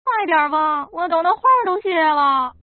Index of /mahjong_phz_test/update/1050/res/sfx_pdk/woman/